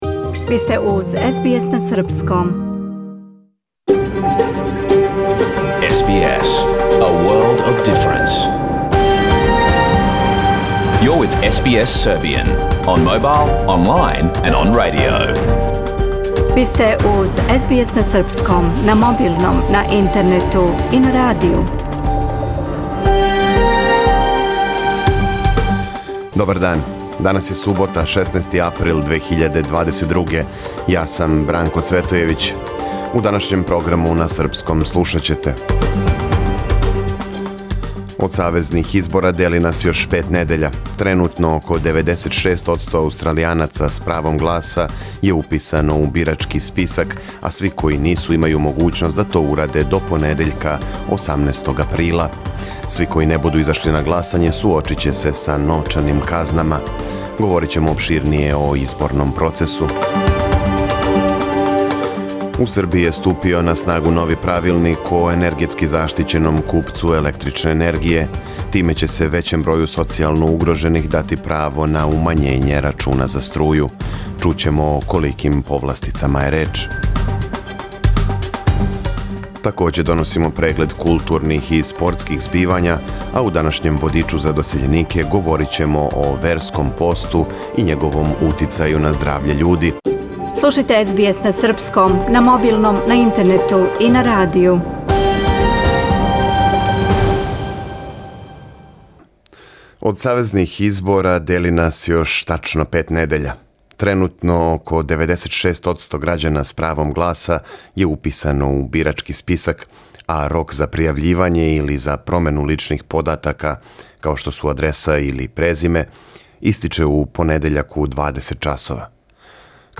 Програм емитован уживо 16. априла 2022. године